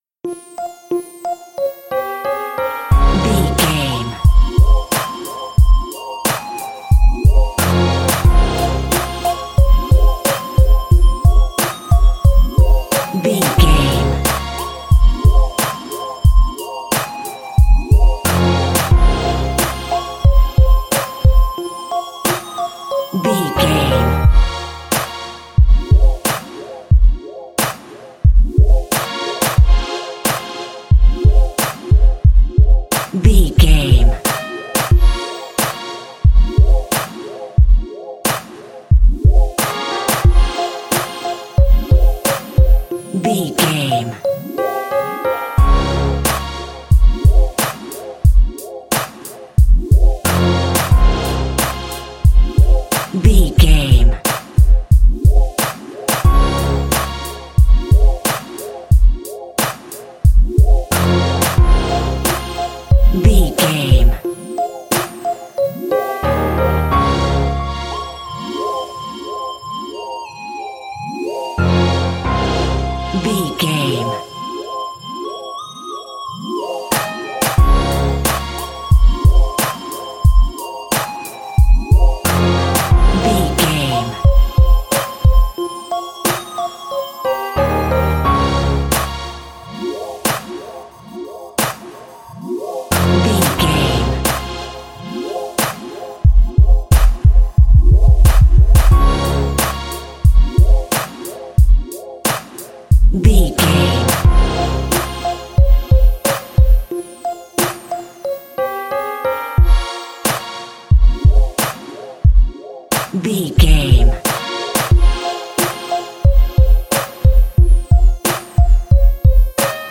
Aeolian/Minor
DOES THIS CLIP CONTAINS LYRICS OR HUMAN VOICE?
drum machine
synthesiser